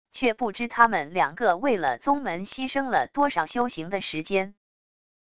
却不知她们两个为了宗门牺牲了多少修行的时间wav音频生成系统WAV Audio Player